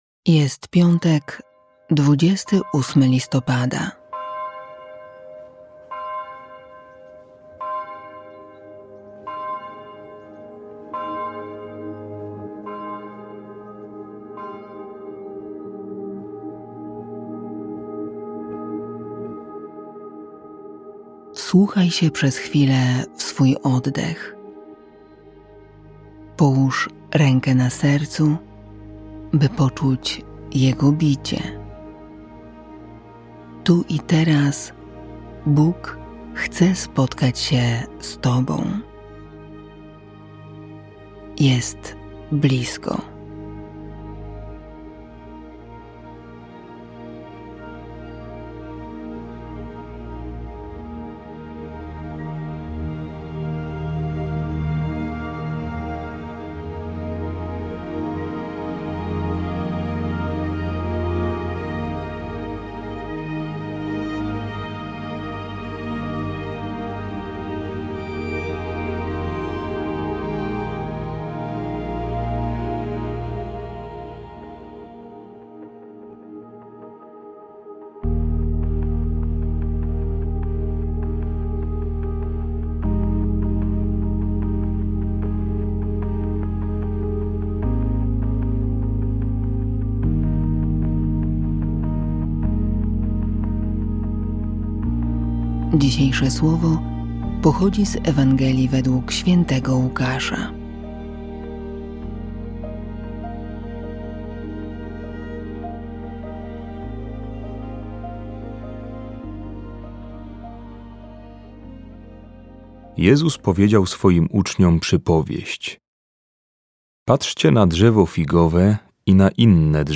Kazanie audio – Parafia NSPJ Kęty – Osiedle